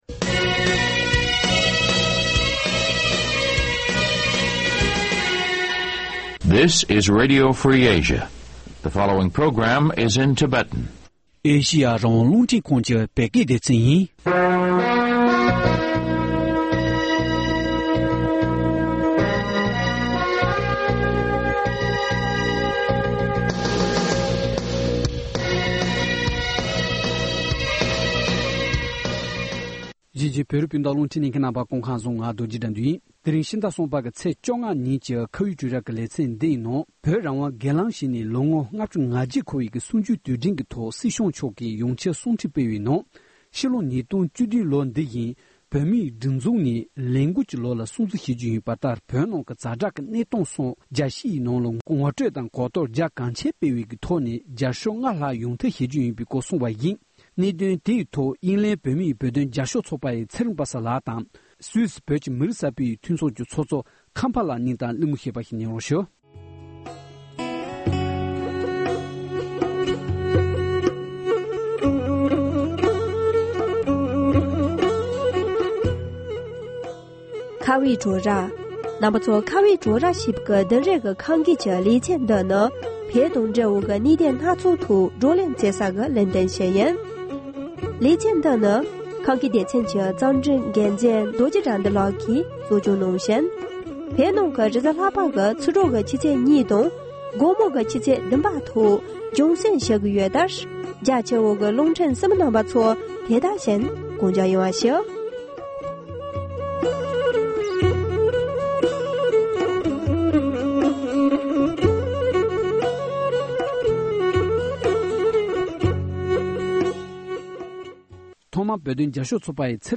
འབྲེལ་ཡོད་ཁག་གཅིག་དང་གླེང་མོལ་ཞུས་པར་གསན་རོགས་གནང་།